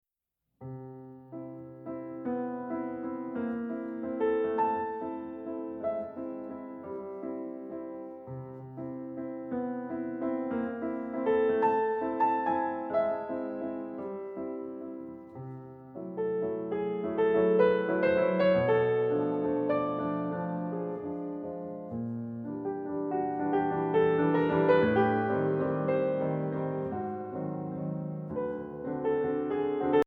Voicing: Piano with Audio Access